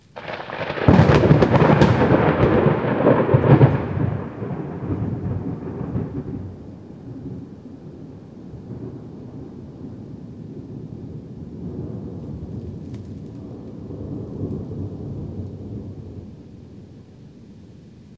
thunder-5.ogg